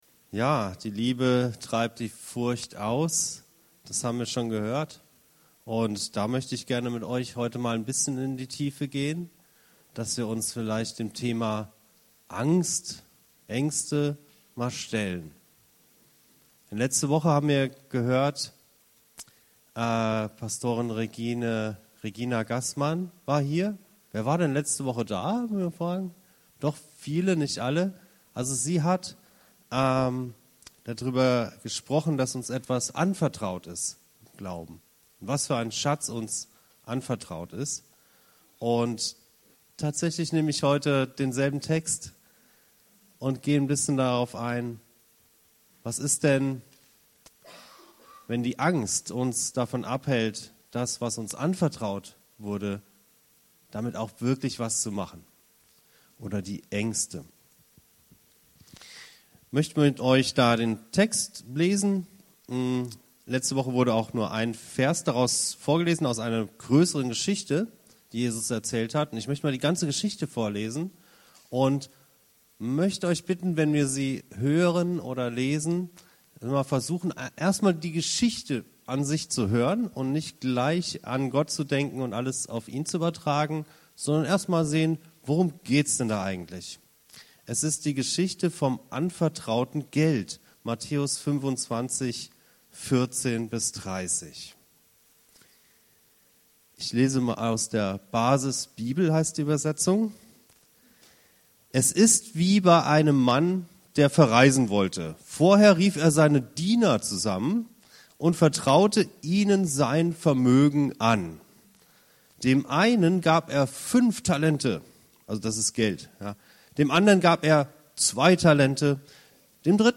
Eine motivierende Predigt im Rahmen der Allianzgebetswoche.